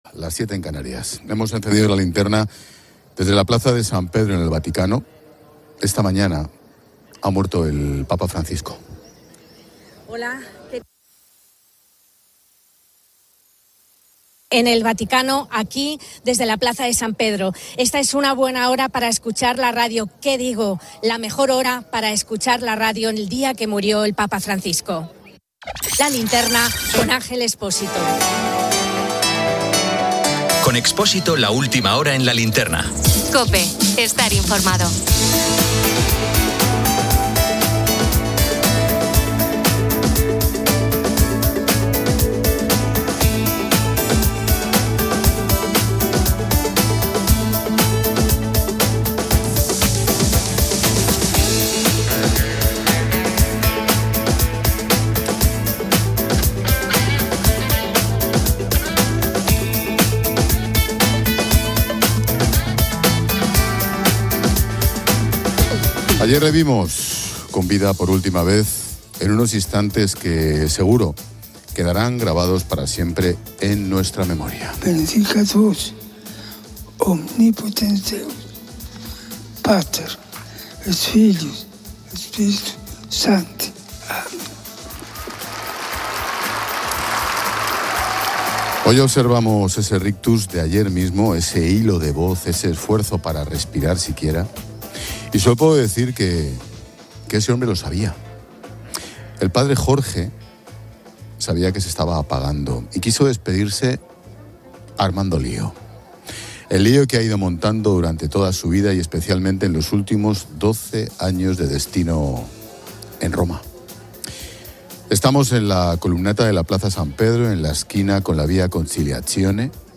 Hemos encendido la linterna desde la Plaza de San Pedro en el Vaticano. Esta mañana ha muerto el Papa Francisco.